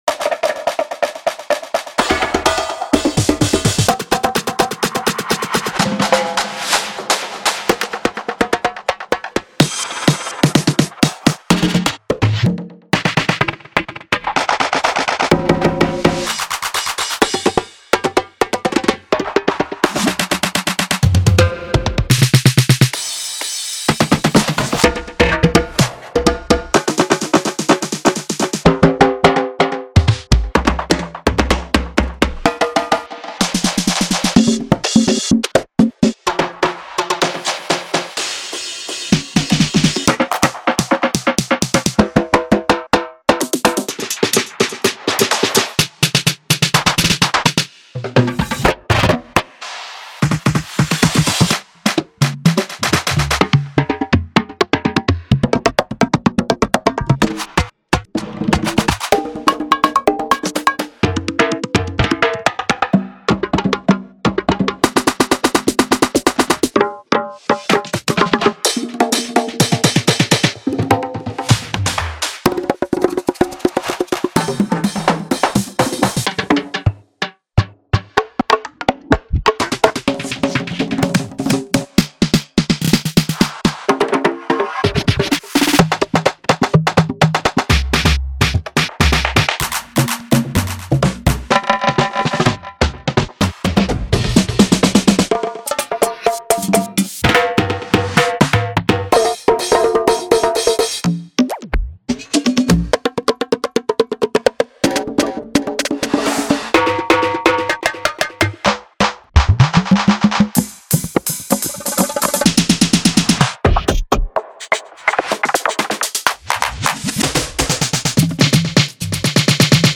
Genre:House
ローリングドラムやパリッとしたスネアからリバースシンバル、コンガ、ミスクセッション、マイクロビート、そしてアップリフティングなエフェクトまで、すべてのサウンドはあなたの音楽にシームレスに溶け込むように慎重に制作されています。
テンポは125から128 BPMで、テックハウス、アフロハウス、ディープハウス、ベースハウス、ビッグルームなど、さまざまなハウスサブジャンルに適したフィルを提供します。
ご注意ください：オーディオデモは、音が大きく、圧縮され、均一に処理されています。